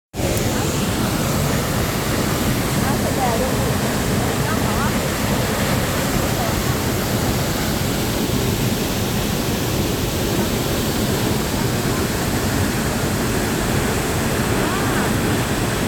Après un trajet chaotique de plusieurs heures en bus, nous sommes arrivés au parc naturel du Salto del Buey (le saut du bœuf).Nous avons alors entamé une excursion épique dont le point d’arrivée était cette cascade extraordinaire :
C’est une occasion unique de recréer, grâce aux sons enregistrés dans un lieu, l’atmosphère singulière que celui-ci possède.
cascade-colombie.mp3